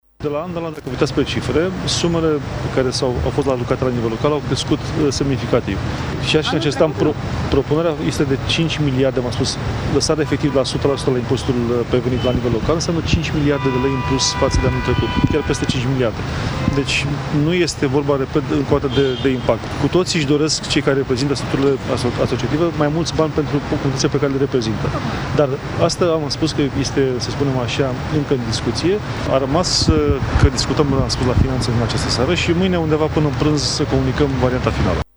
De asemenea, Teodorovici a precizat că este normal ca primarii să își dorească cote mai mari din impozitul pe venit: